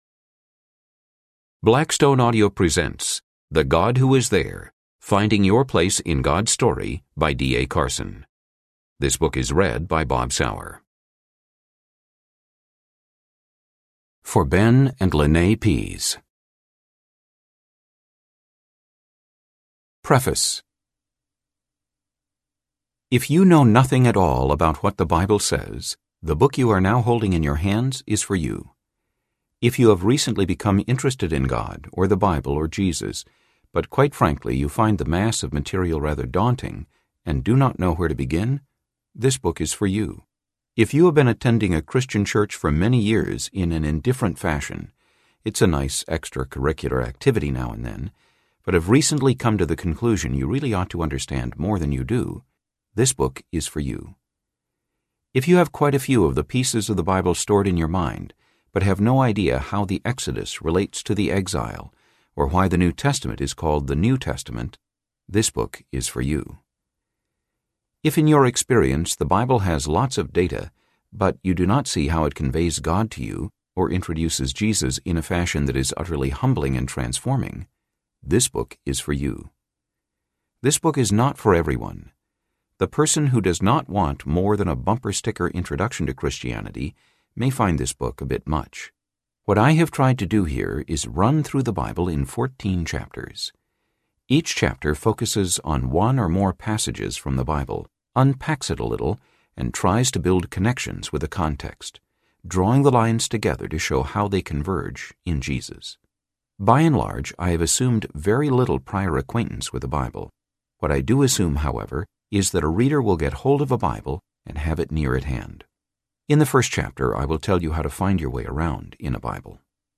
The God Who Is There Audiobook